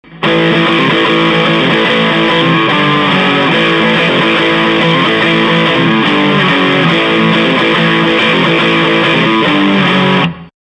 BM BOOSTER ON(171kb,MP3)
Guitar Moon TeleType PU MIX
Amplifier VOX AD30VT AC15,GAIN10
VOLUME10,TREBLE5,MIDDDLE5,BASS5
録音はSM５７をあえて使用せず、「普通のマイク」です。
しかもPCのマイク端子に直挿しです。
BM BOOSTERも、つまみは両方ともフル１０です。